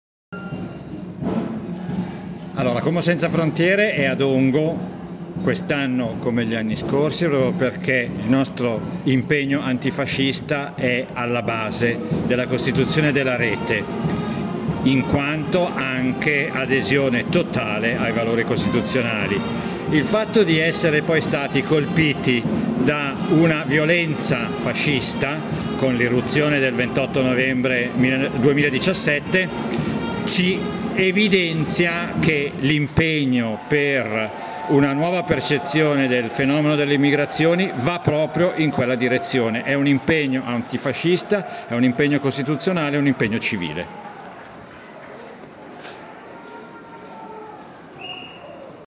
Dichiarazioni alla fine della manifestazione